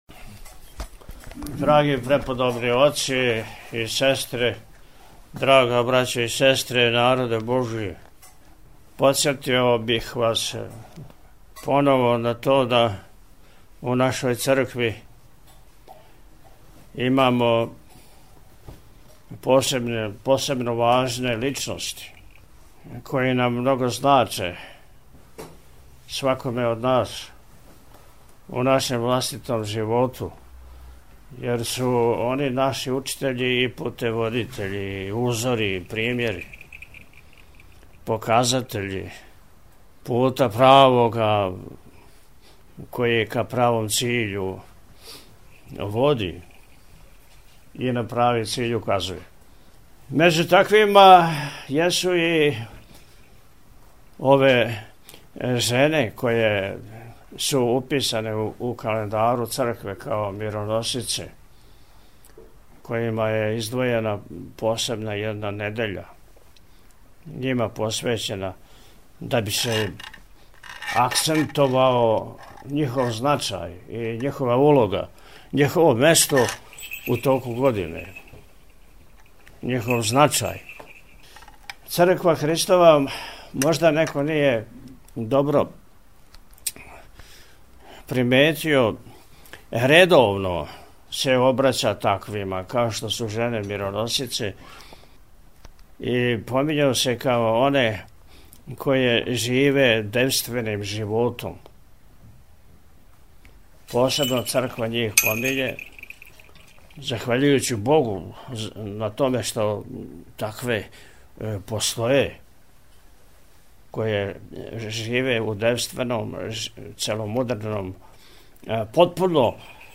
О првим сведоцима Христовог Васкрсења, Високопреосвећени је говорио у пастирској беседи: – У нашој Цркви имамо посебно важне личности које нам много значе, свакоме од нас, јер су они наши учитељи и путеводитељи, узори и примери, показатељи правога пута, који ка правом циљу води. Међу таквима јесу и ове жене које су уписане у календару Цркве као Мироносице.